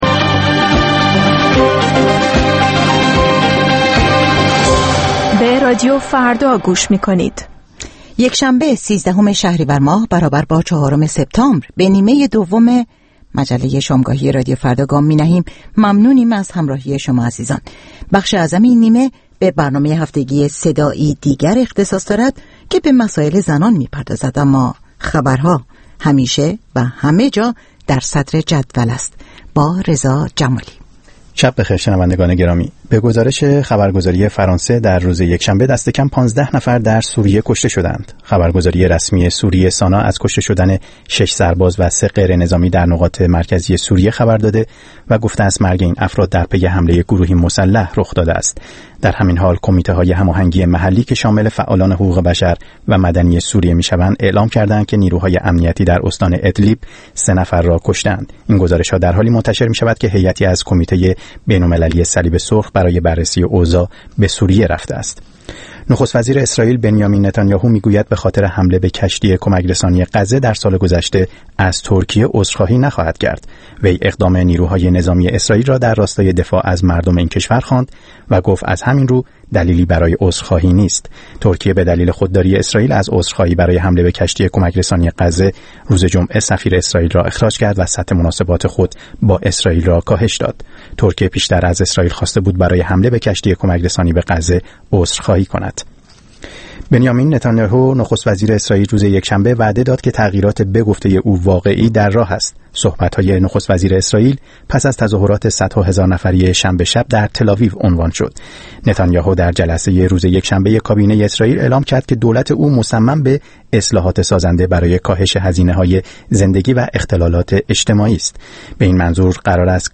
صدای دیگر، مجله ای هفتگی در رادیو فردا است که روزهای یکشنبه هر هفته در ساعت هفت ونیم بعد از ظهر (به وقت تهران) در نیمه دوم مجله شامگاهی رادیو فردا پخش میشود.